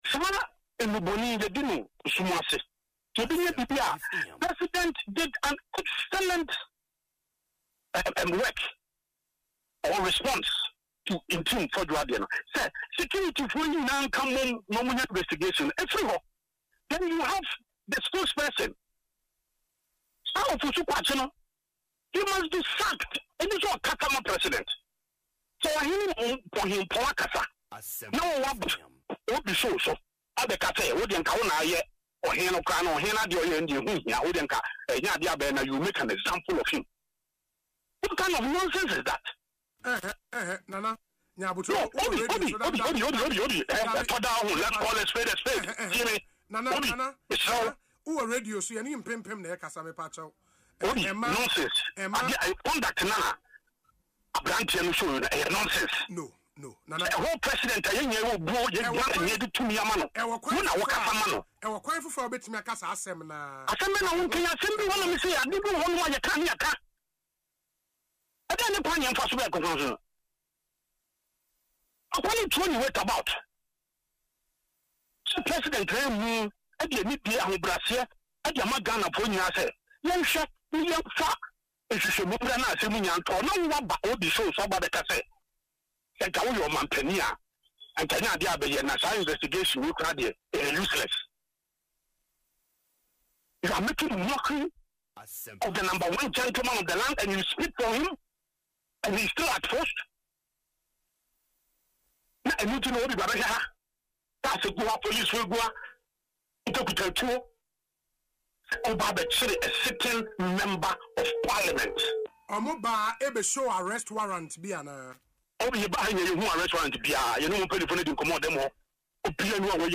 Speaking in an interview on Asempa FM’s Ekosii Sen, Nana B expressed strong disapproval over comments made by Mr. Kwakye Ofosu concerning recent allegations of drug trafficking and money laundering involving two aircraft that transited through Ghana.